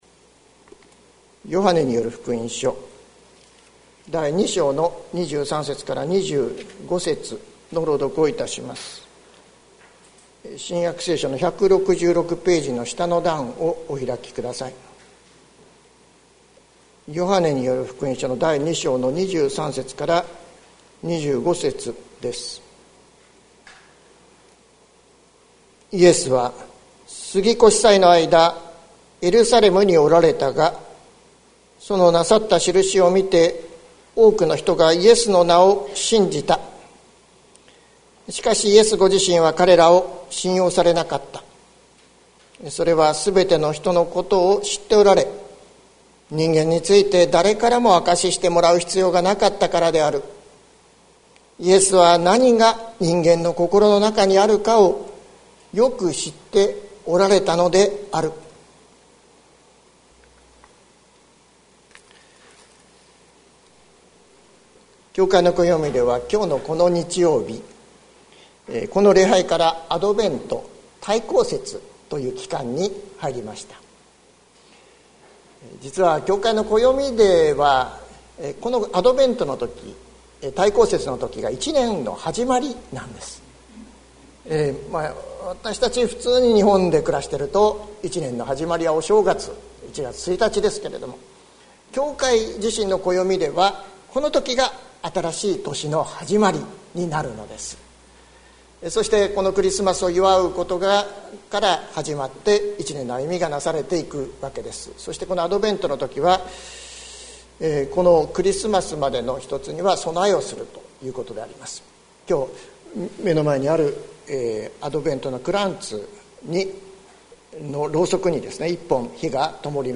2021年11月28日朝の礼拝「人のこころ、イエスのこころ」関キリスト教会
説教アーカイブ。